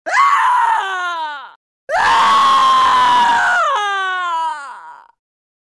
MK9 Sindel EXTREME BOOST K.O. Scream
mk9-sindel-extreme-boost-k-o-scream.mp3